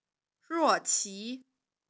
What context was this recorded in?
Mandarin_Voiceprint_Recognition_Speech_Data_by_Mobile_Phone